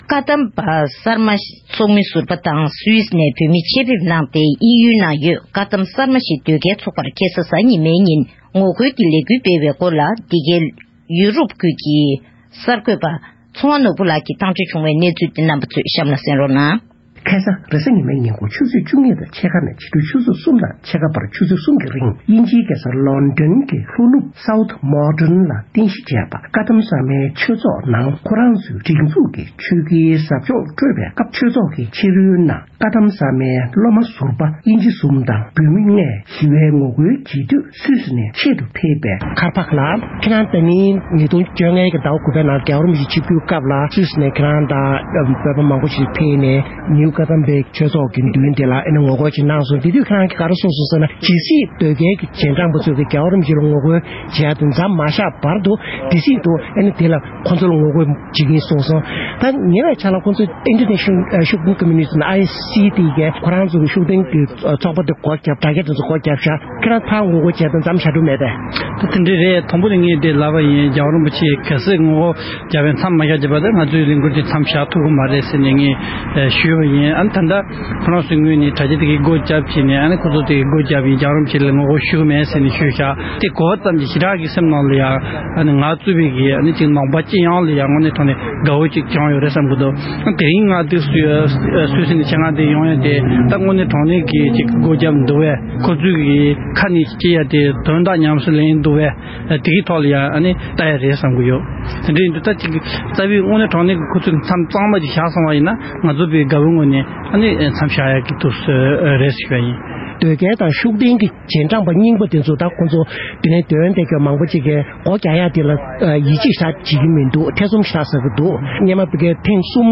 དཀའ་གདམས་གསར་མའི་ཆོས་ཚོགས་ལ་ངོ་རྒོལ། དབྱིན་ཡུལ་ནང་གི་དཀའ་གདམས་གསར་མའི་ཆོས་ཚོགས་ལ་ངོ་རྒོལ་བྱེད་མཁན་ལ་བཅར་འདྲི་ཞུས་པ།